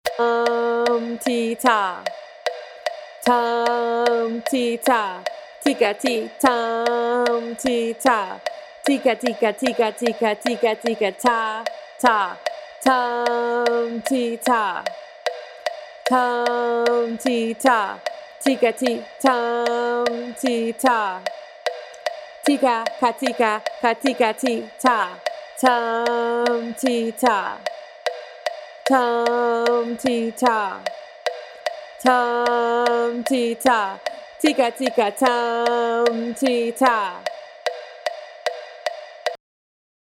In this example you will use the Kodály Method to read through a rock rhythm that uses a combination of simple rhythms and syncopation.
Rock Rhythm: Spoken
TR6-ROCK-RHYTHM.mp3